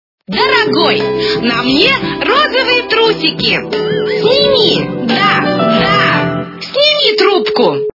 » Звуки » Смешные » Дорогой на мне розовые трусики! - Сними трубку!
При прослушивании Дорогой на мне розовые трусики! - Сними трубку! качество понижено и присутствуют гудки.